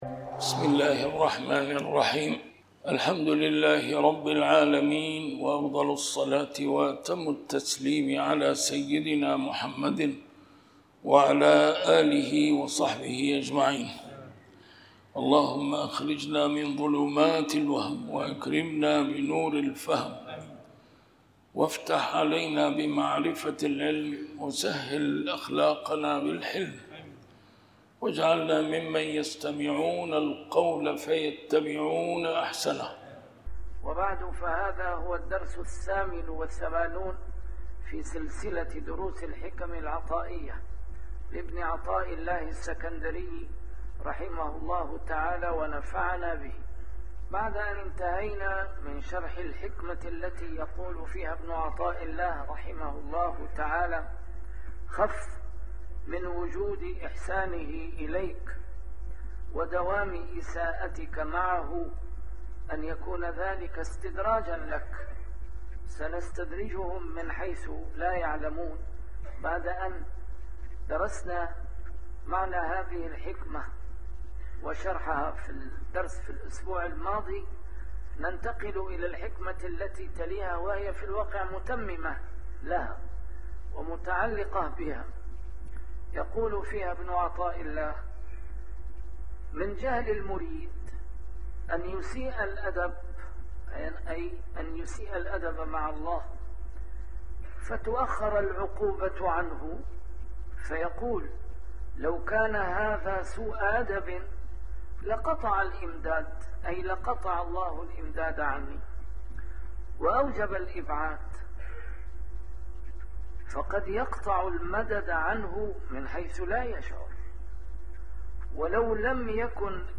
A MARTYR SCHOLAR: IMAM MUHAMMAD SAEED RAMADAN AL-BOUTI - الدروس العلمية - شرح الحكم العطائية - الدرس رقم 88 شرح الحكمة 66